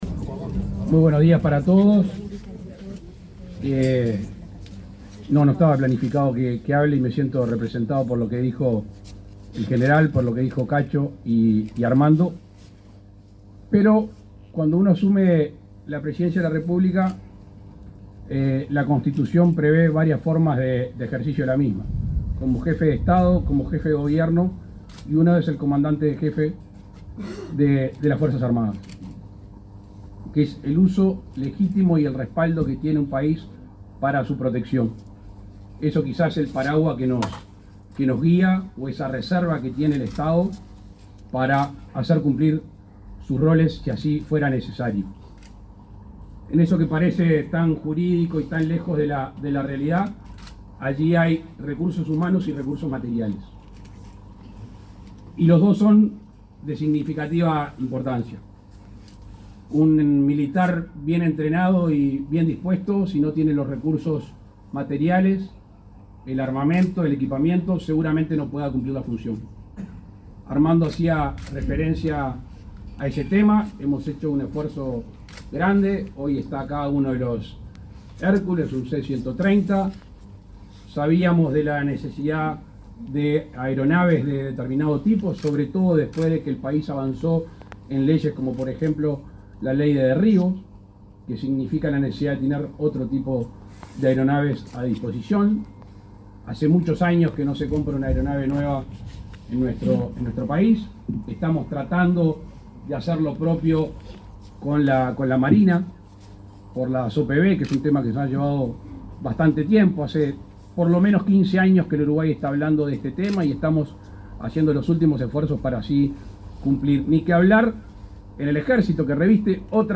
Palabras del presidente Luis Lacalle Pou
El presidente de la República, Luis Lacalle Pou, participó, este miércoles 11, en la inauguración del Centro de Especialidades Regional n.° 13, en el